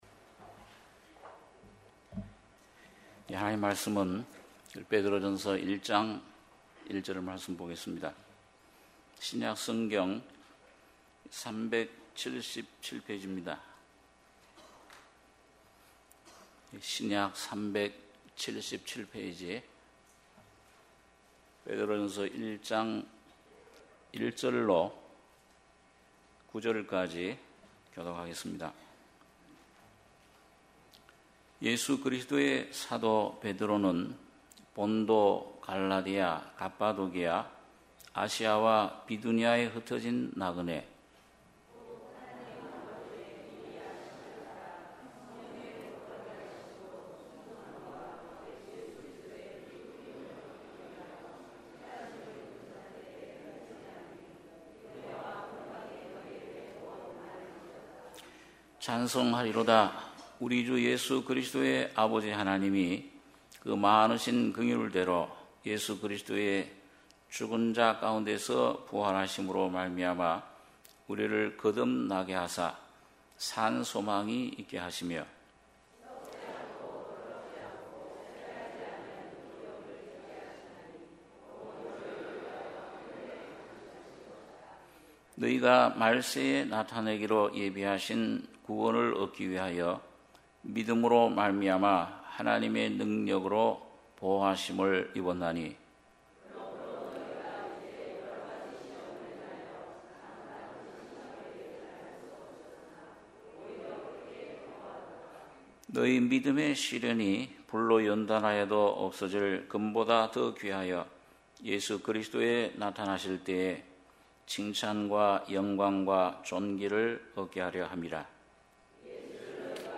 주일예배 - 베드로전서 1장 1절-9절